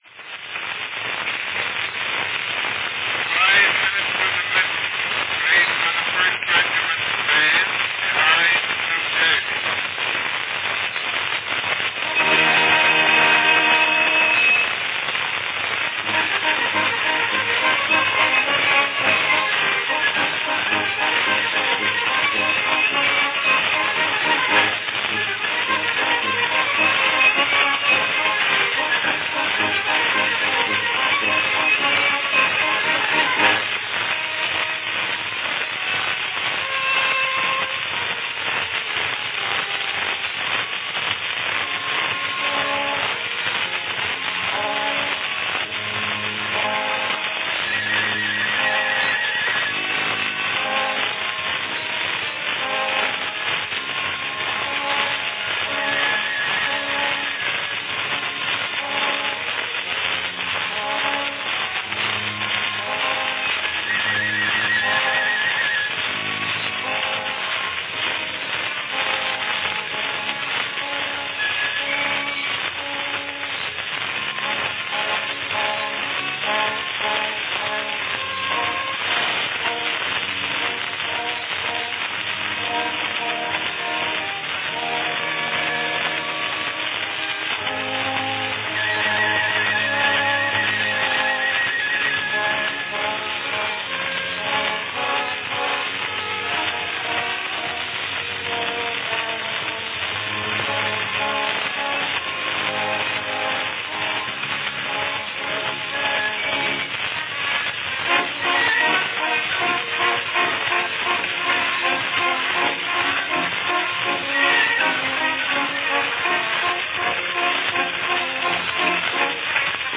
An over 130 year old brown wax cylinder recording
Category Band